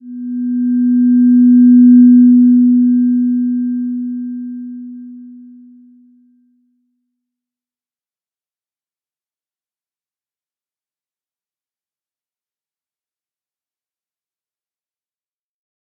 Slow-Distant-Chime-B3-p.wav